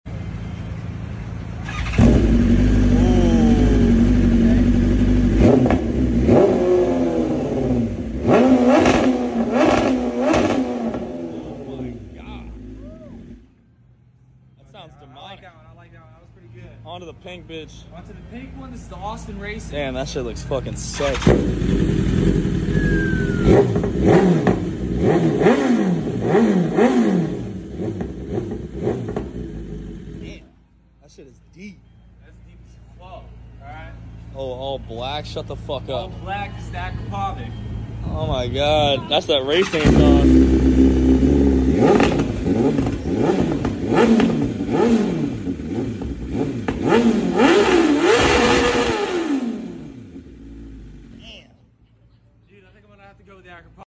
The Loud Roar Of 3 sound effects free download